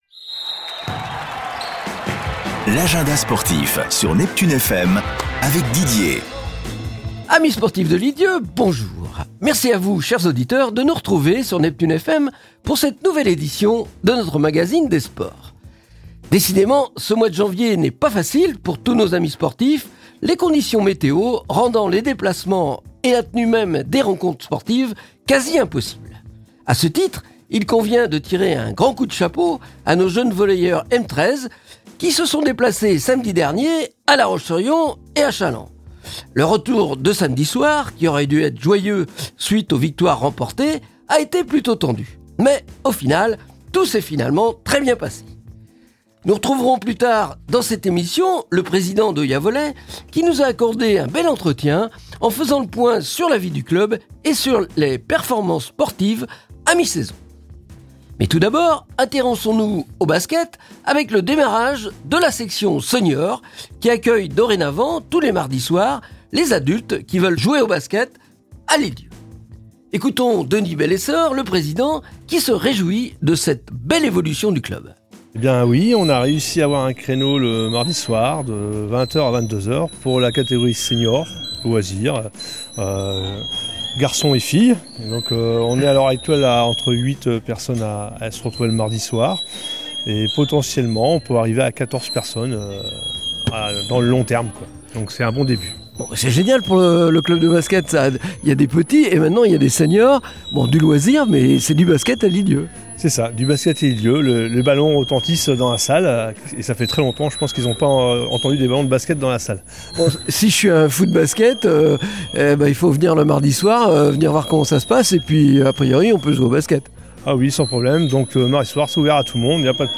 un long entretien